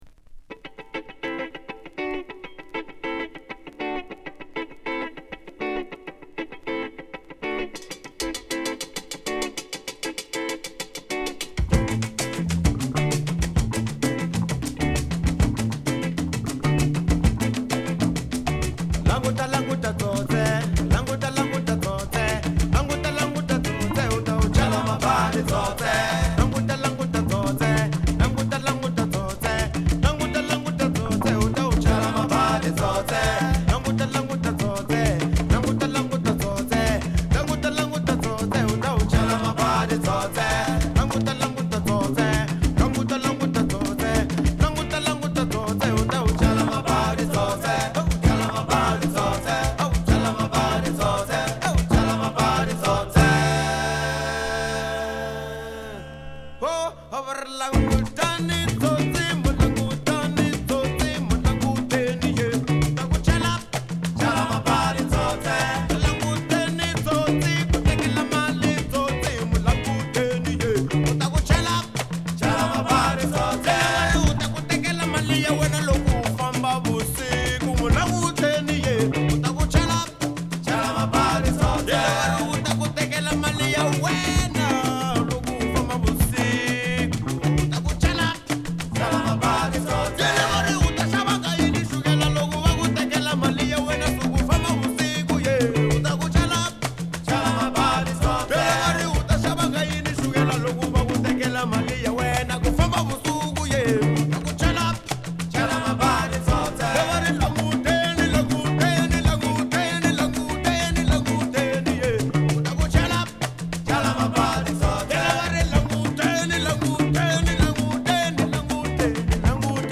パーカッションとベースが土台となるアフロトラックにエフェクトでトバす、エレクトリックなホーン&コーラスが絡む